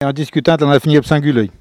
parole, oralité